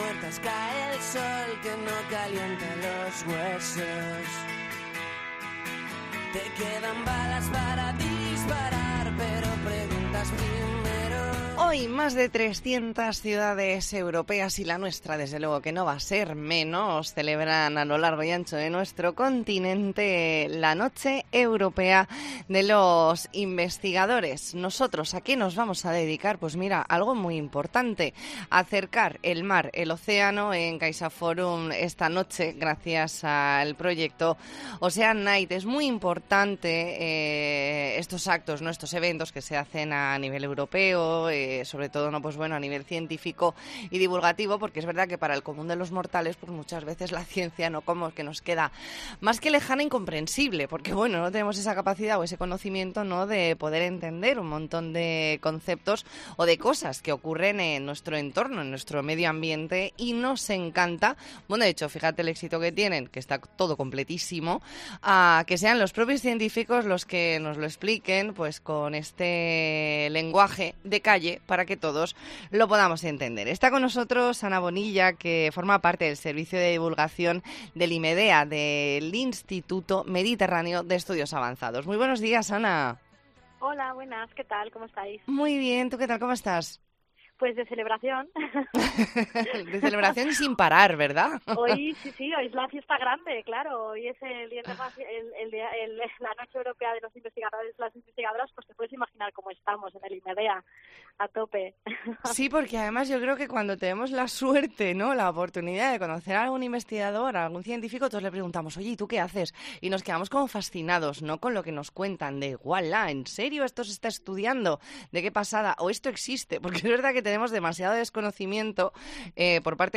ntrevista en La Mañana en COPE Más Mallorca, viernes 30 de septiembre de 2022.